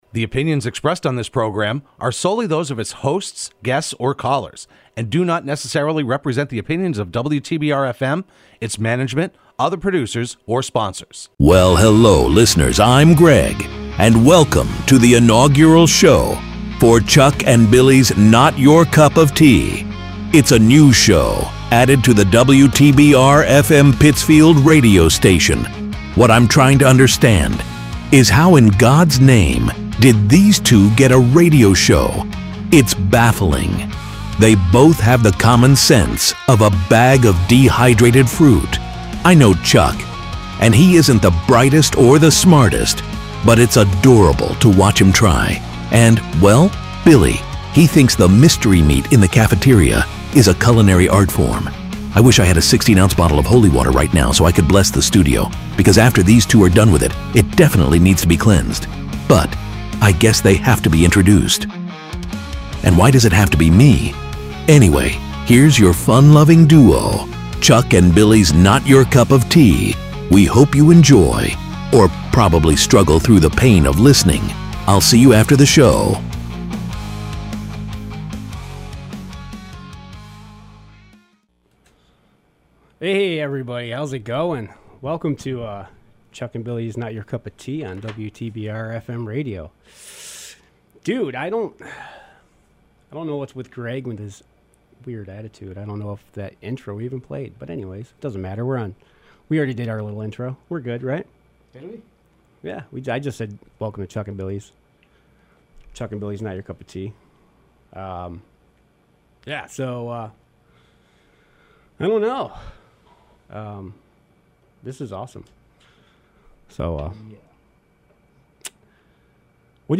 Broadcast live every Wednesday afternoon at 4pm on WTBR.